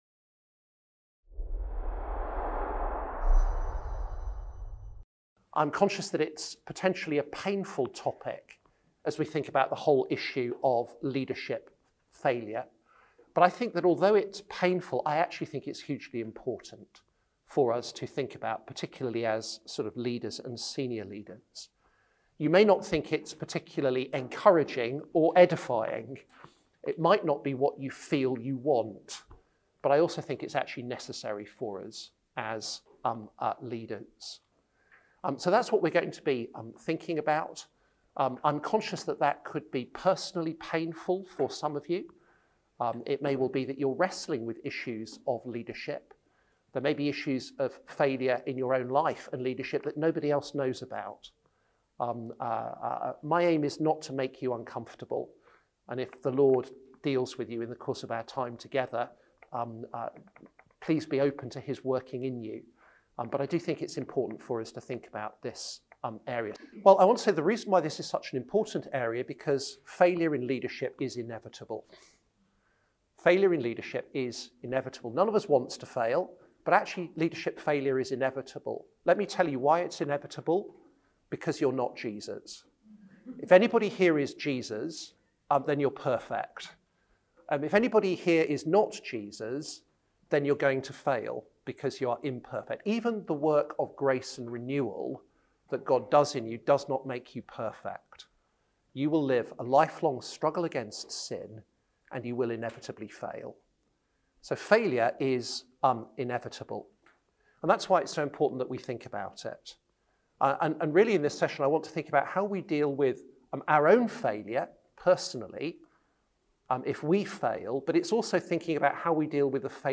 Event: ELF Leaders of Christian Organisations Network